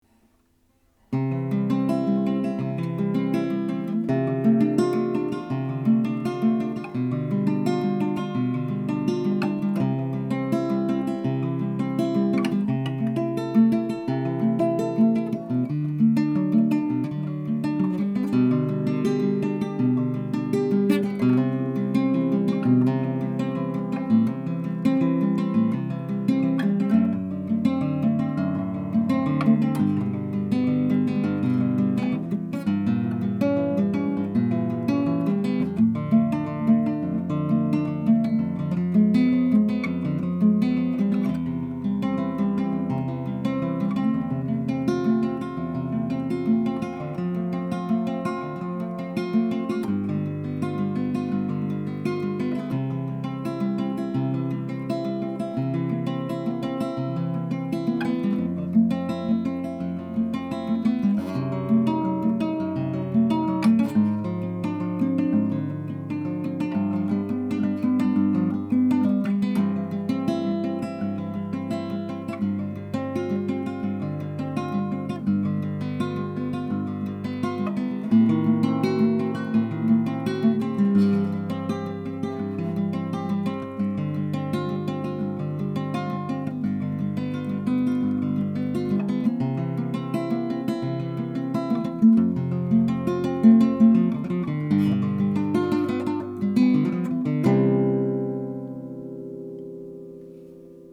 До-мажор